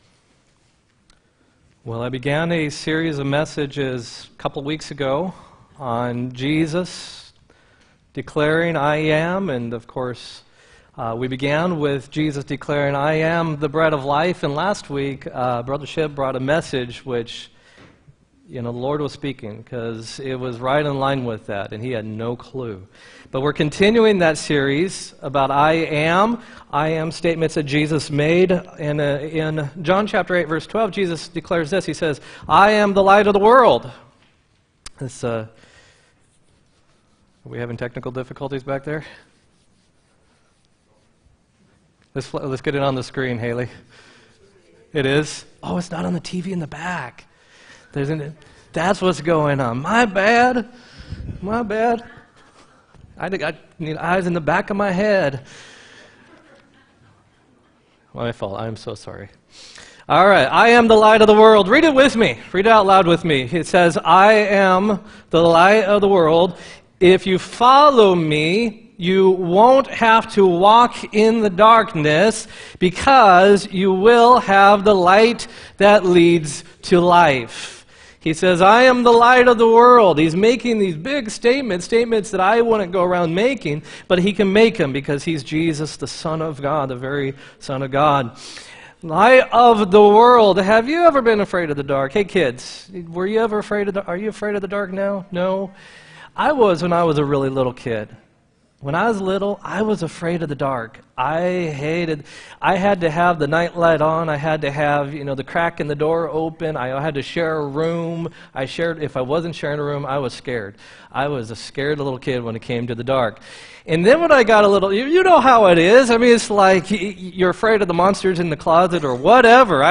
10-21-17 sermon